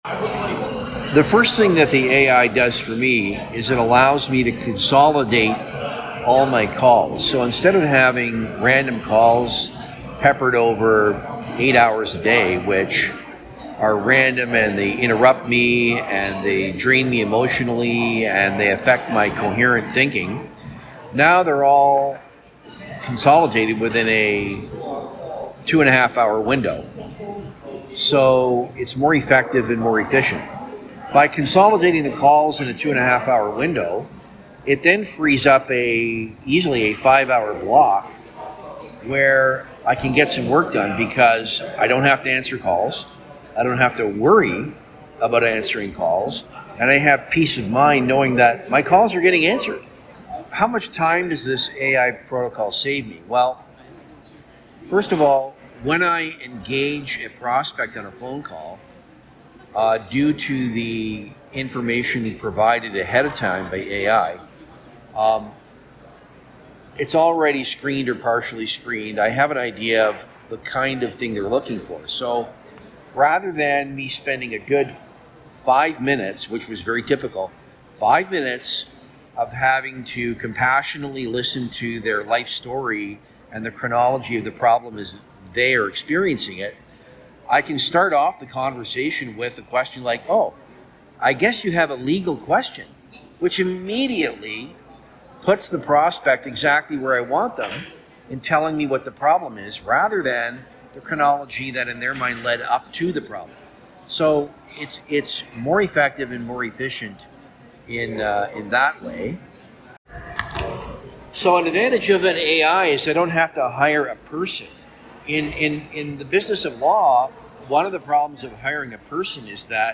In The Client’s Words (click to play audio)
YLAW_TESTAMONIAL_REVIEW.mp3